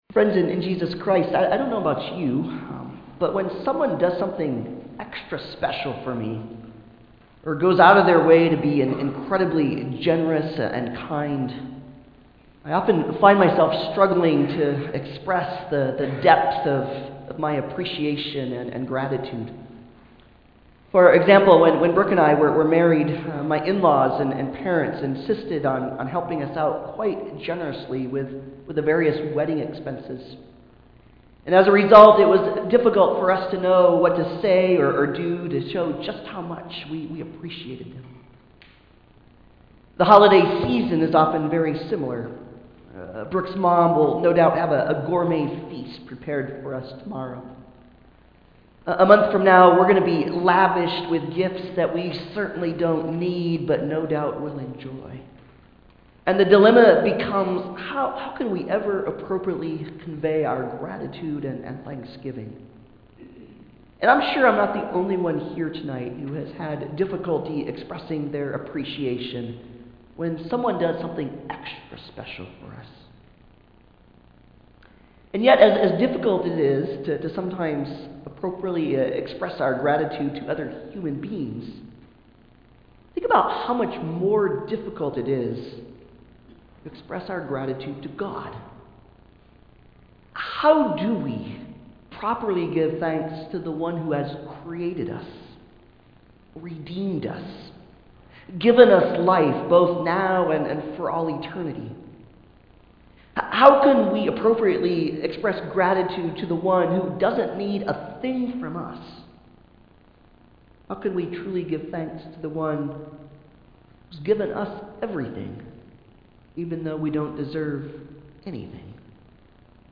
Passage: Psalm 100:1-5 Service Type: Holiday Service « Who Do We Listen To?